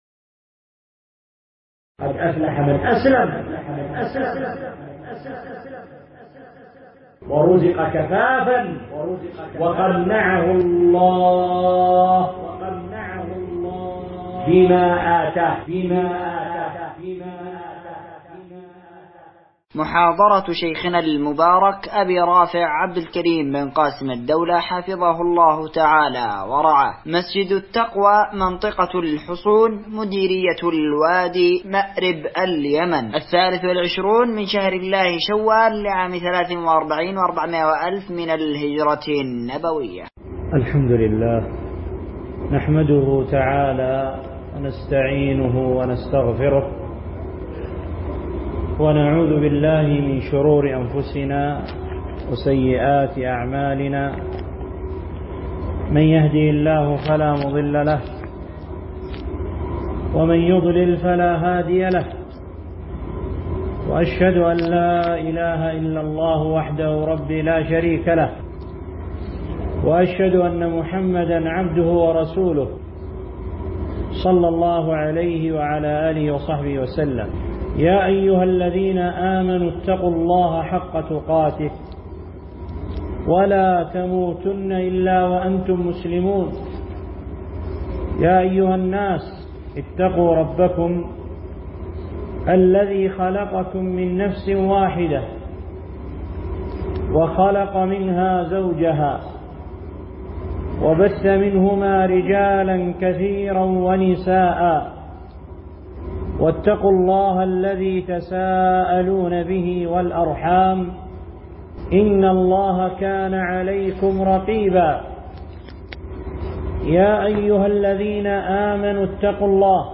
قد أفلح من أسلم محاضرة ← شيخنا المبارك